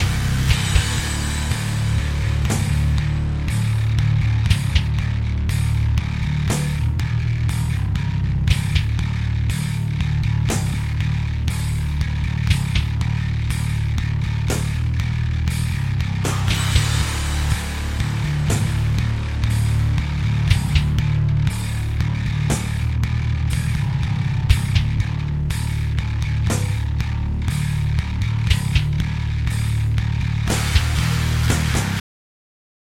Hier mal ein kleines Hörbeispiel wo mans deutlich raushört. Am schlimmsten ists auf der tiefen A-Saite.